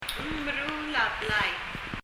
発音
meruul　　[mɛru:l]　　　　修理する fix